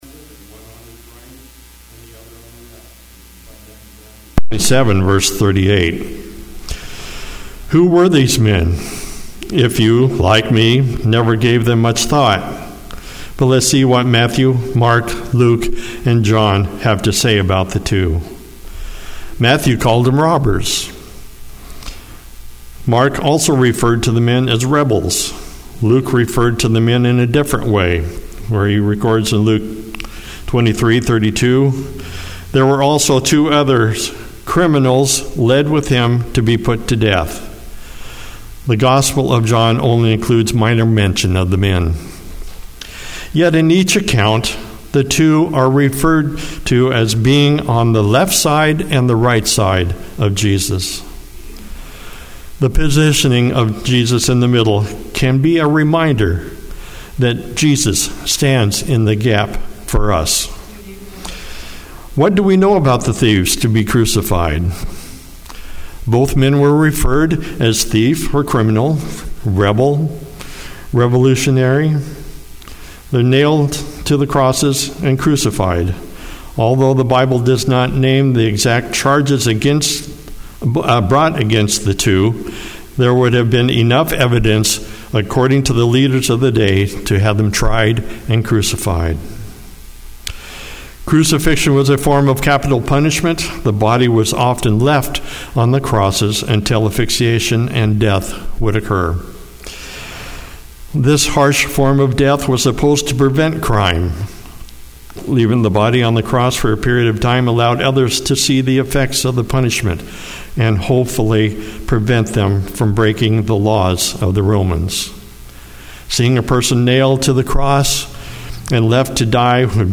Sermons and Talks 2025